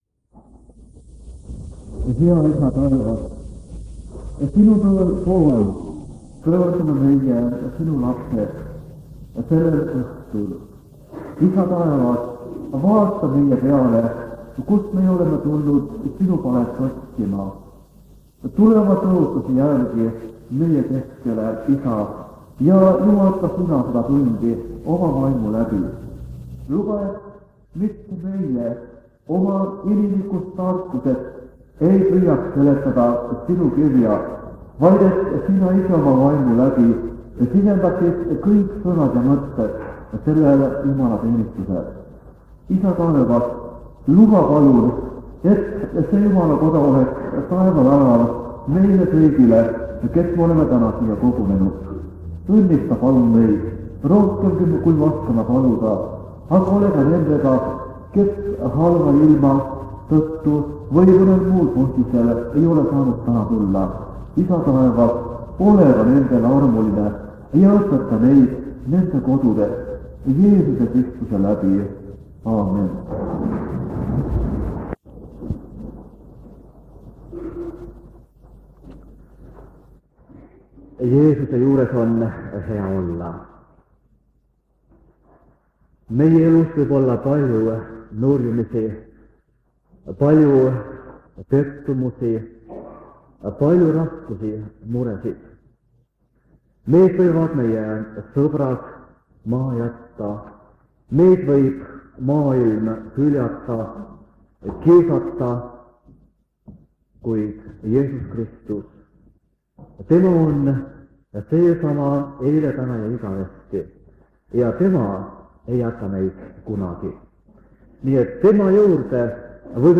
Jutluste miniseeria vanadelt lintmaki lintidelt 1974 aasta sügisel.
Jutlused